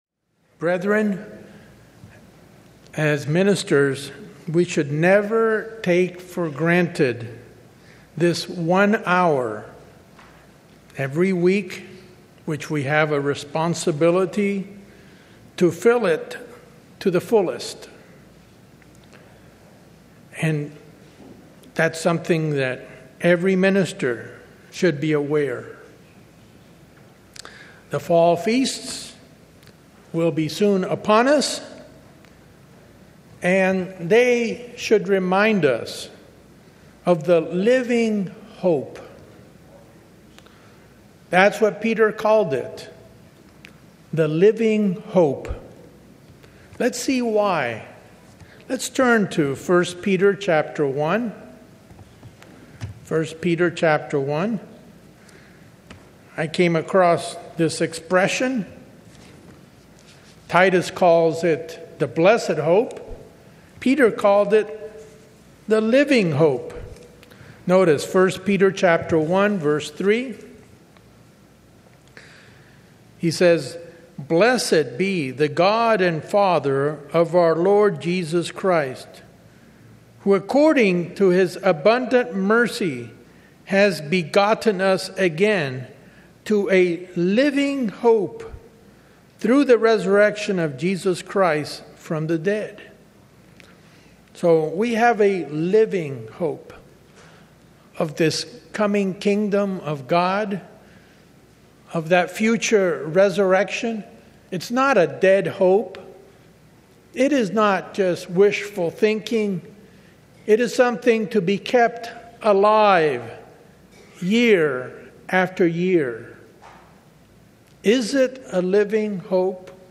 In this sermon we will examine how God foretells the future, and how we can have spiritual eyes to see.